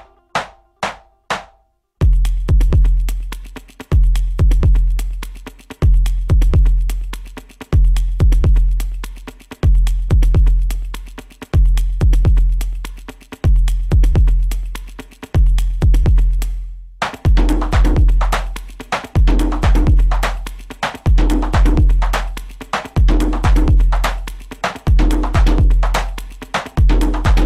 Electro RIngtones